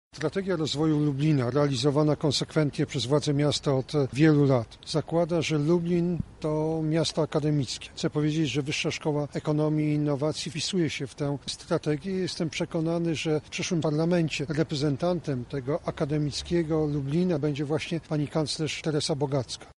— mówi wicepremier Jarosław Gowin